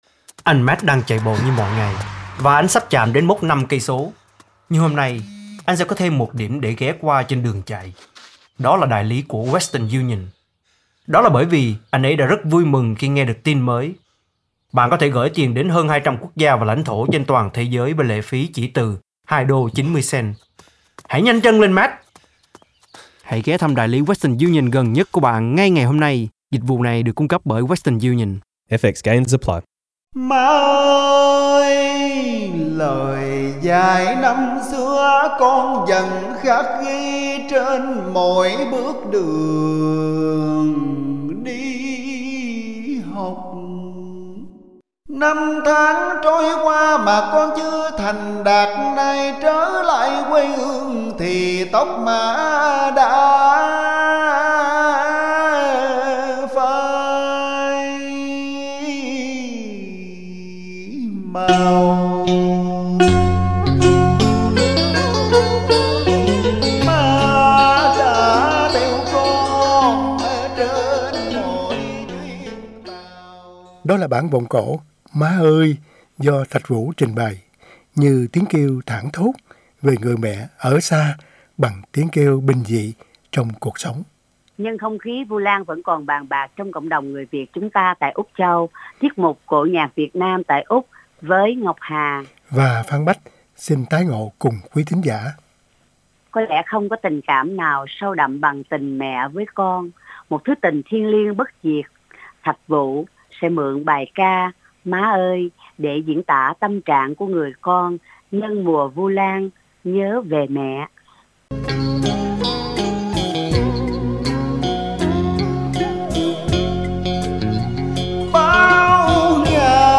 bản vọng cổ